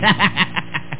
Amiga 8-bit Sampled Voice
Fire.mp3